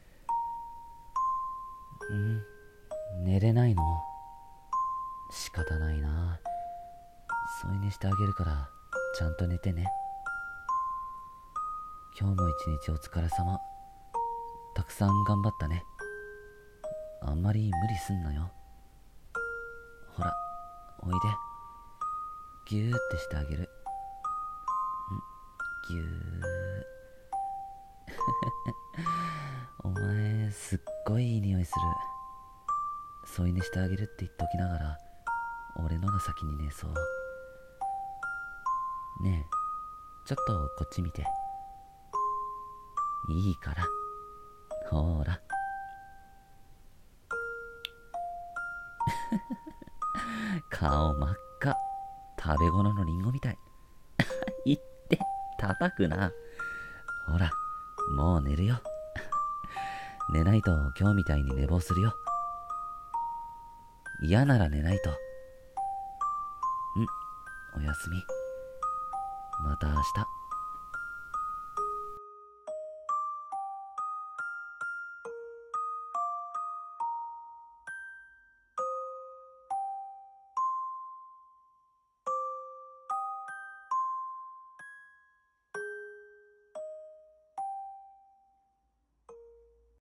【1人声劇】添い寝彼氏